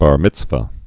(bär mĭtsvə) Judaism